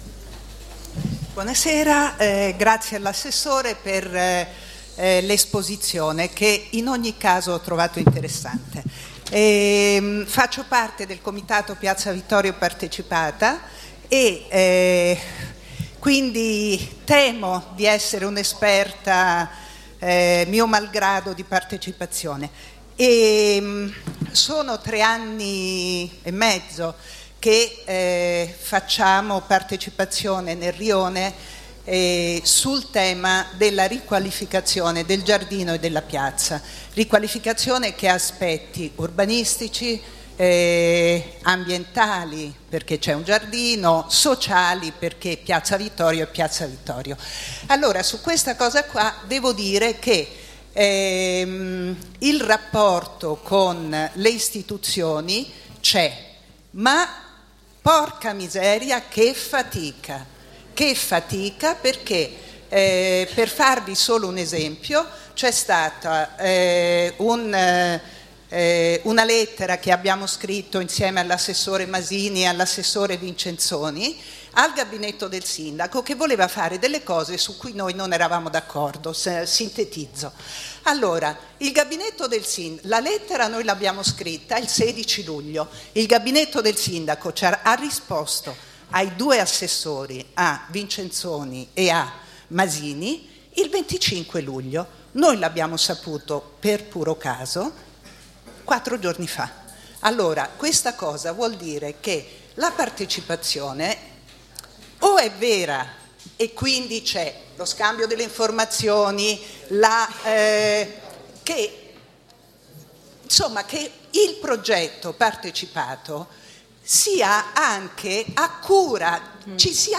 Registrazione integrale dell'incontro svoltosi il 13 ottobre 2014 presso la Casa Internazionale delle Donne, in Via della Lungara, 19.